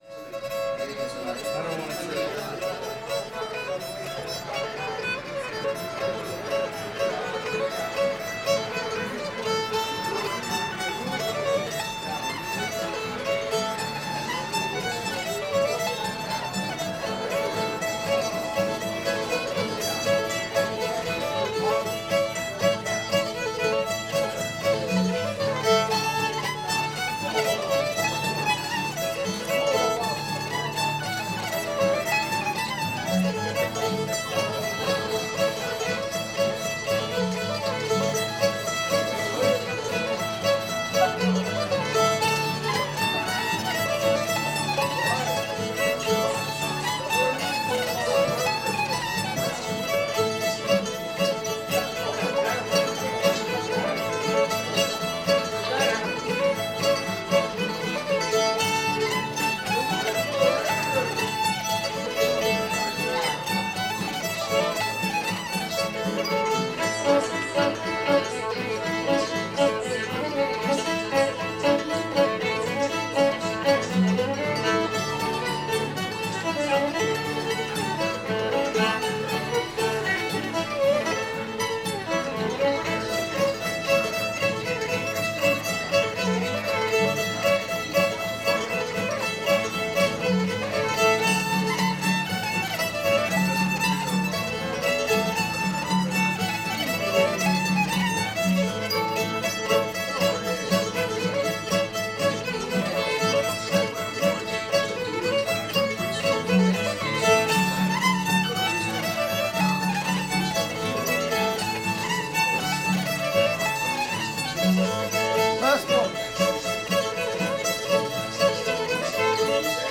rattlesnake [A]